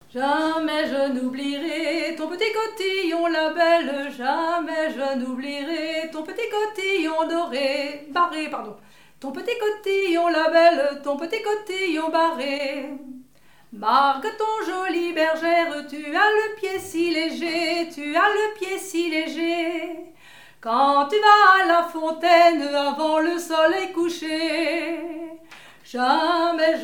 danse : laridé, ridée
Genre laisse
Pièce musicale inédite